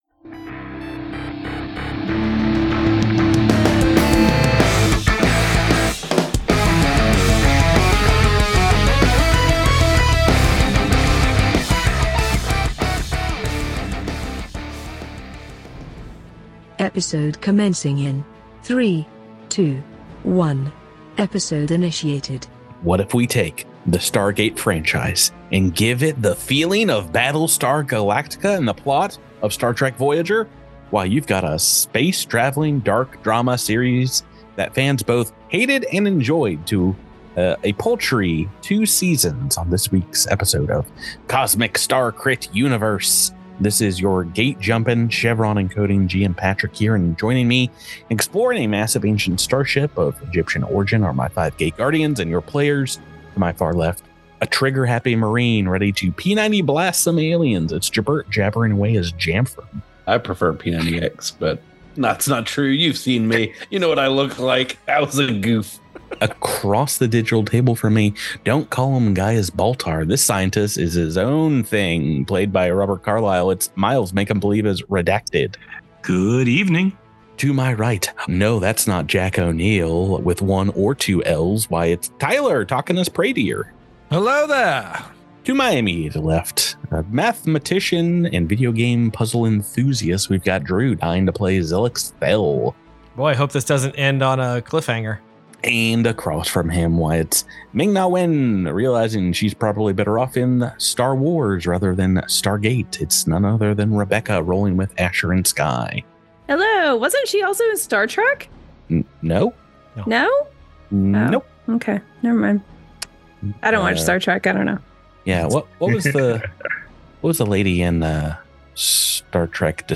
Cosmic Crit is a weekly Actual Play podcast centered on the new Starfinder RPG from Paizo. Listen to the shenanigans as a seasoned GM, a couple of noobs, and some RPG veterans explore the galaxy and fight monsters on behalf of the Starfinder Society.